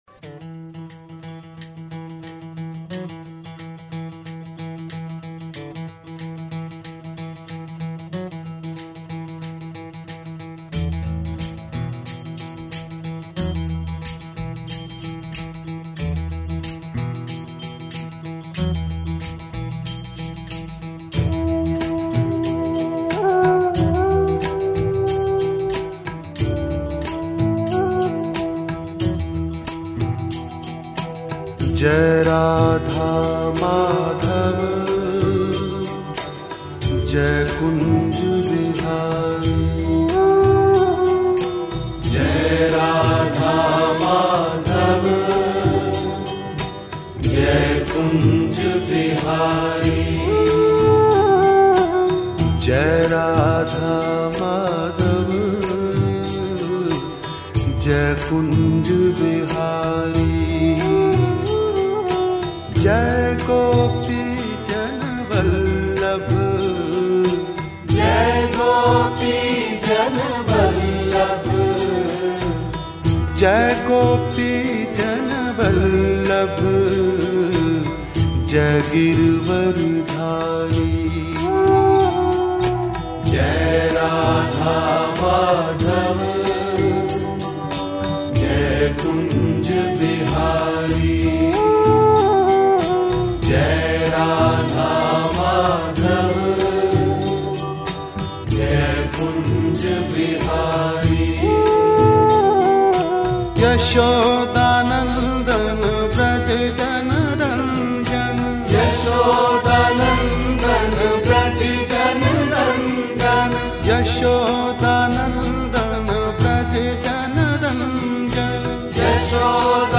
Krishna Bhajans